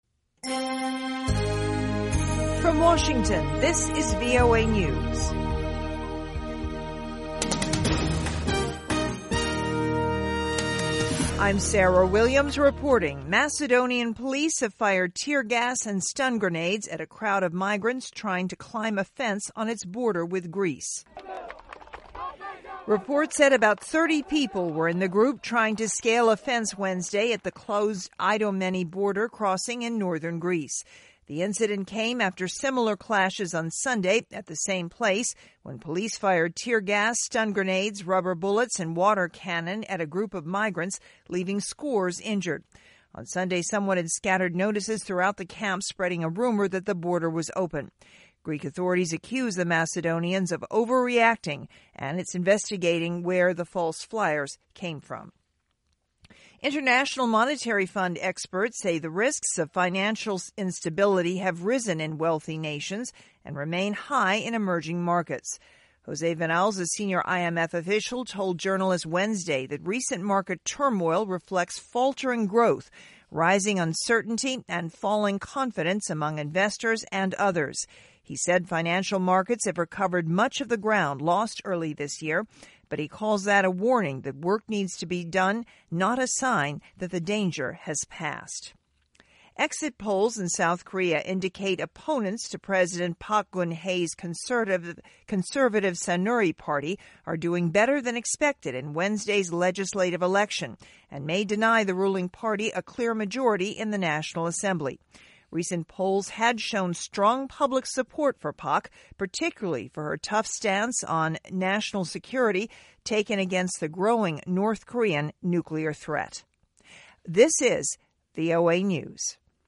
VOA English Newscast: 1600 UTC April 13, 2016